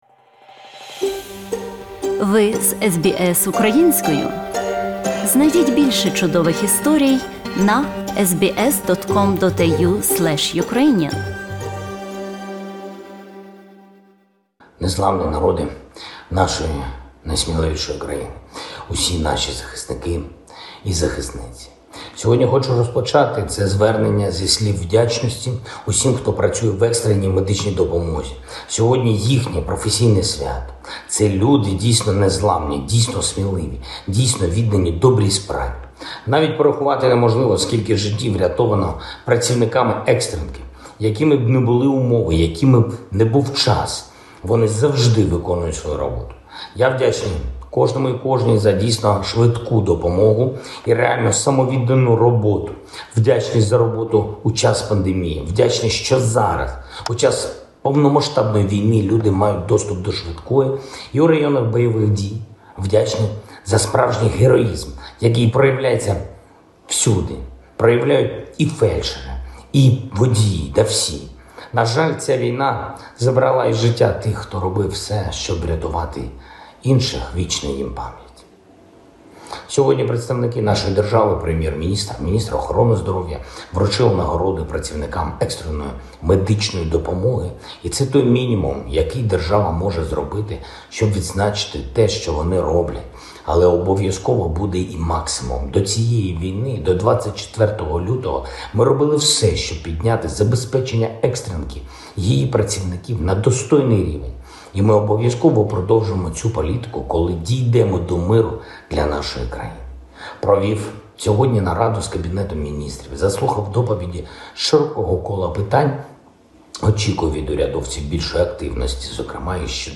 President V. Zelenskyy addresses Ukrainian nation